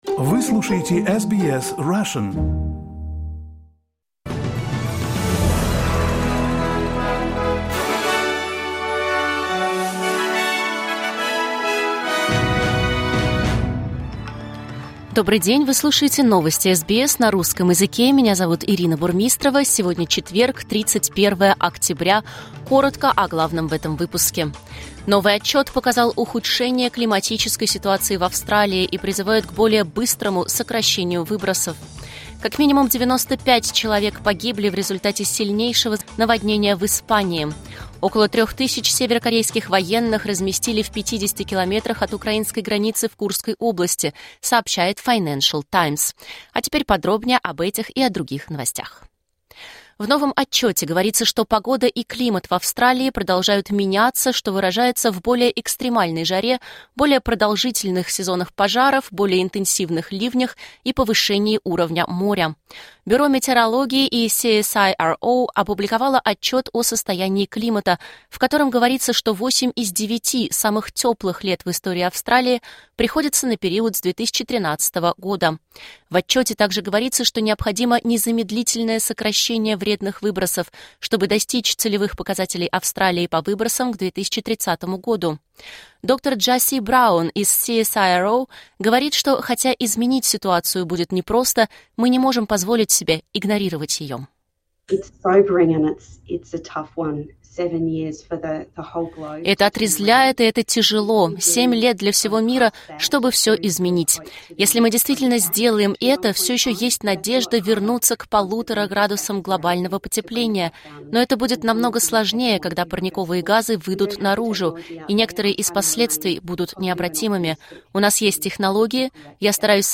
Новости SBS на русском языке — 31.10.2024